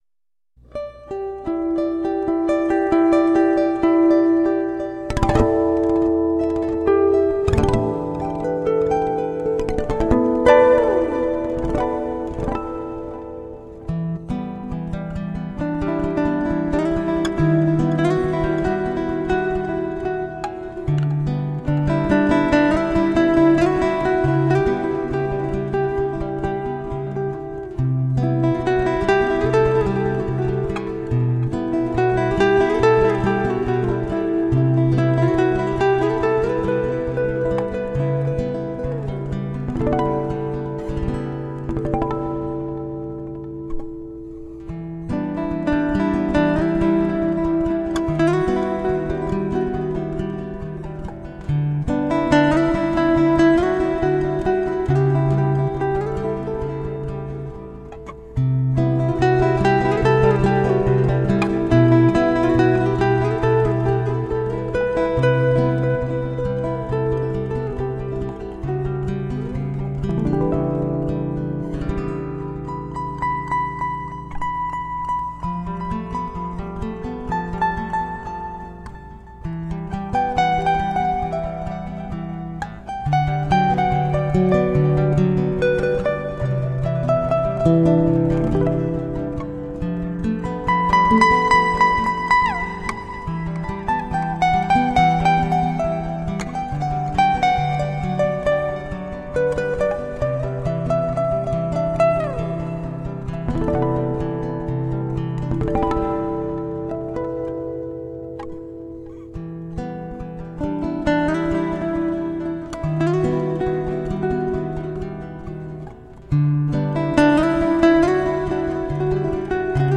Layers of lush acoustic guitar.